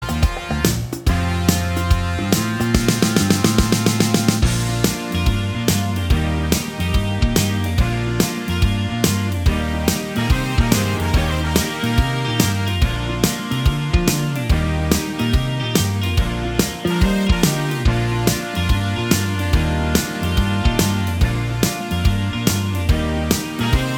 Minus All Guitars Pop (1990s) 3:46 Buy £1.50